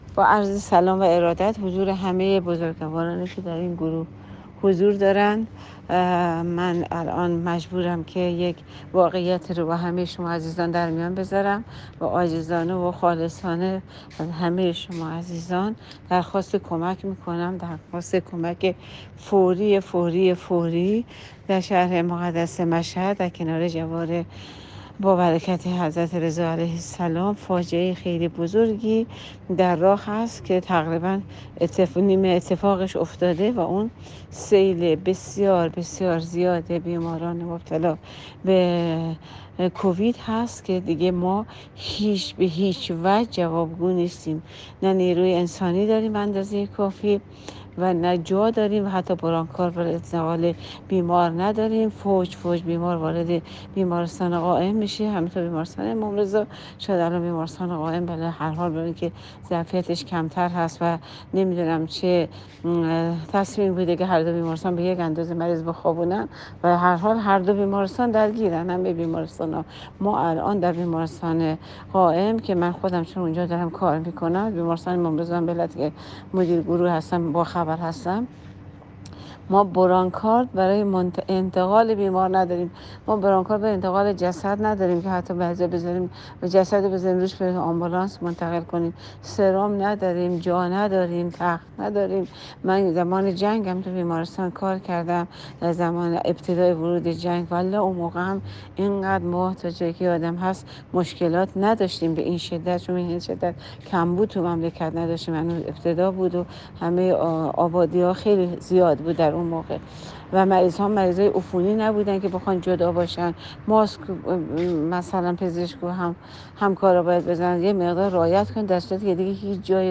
در حال گریه از مردم و مسوولان التماس می کند
با گریه می گوید ما حتی اکسیژن نداریم به این مسلمانها بزنیم.